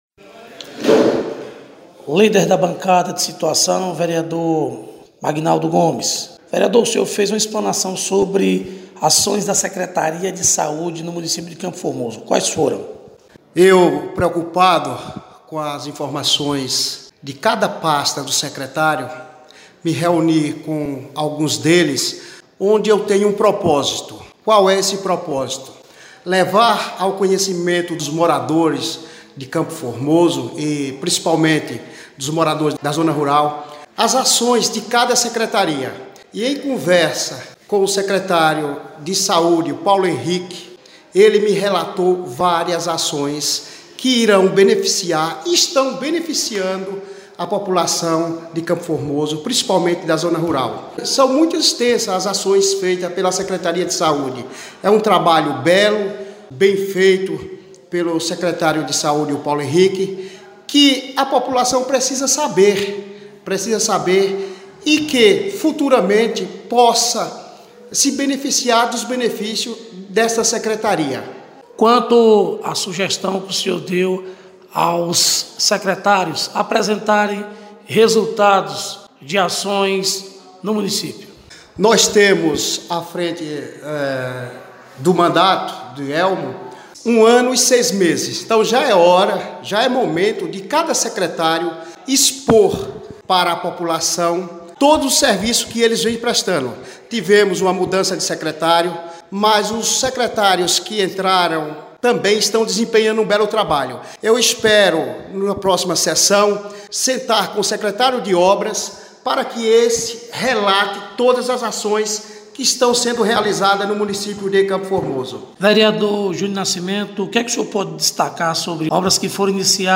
Reportagem – Vereadores do município de CFormoso